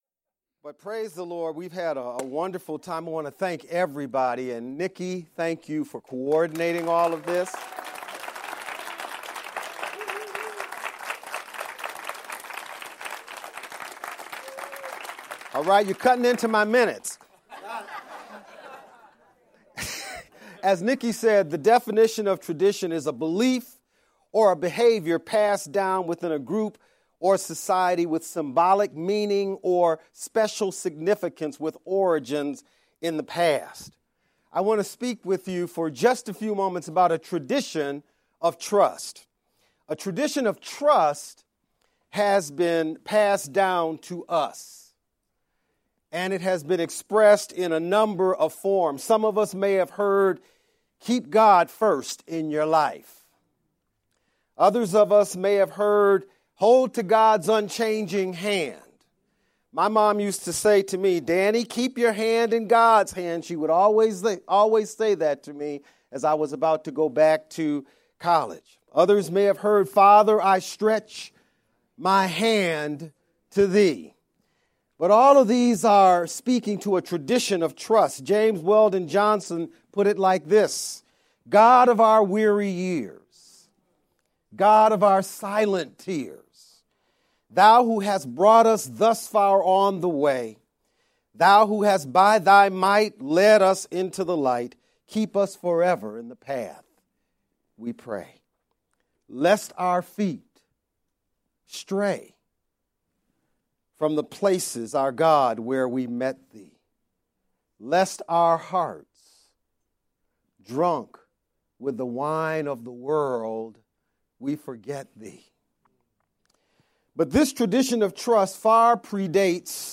Black History Celebration Sermonette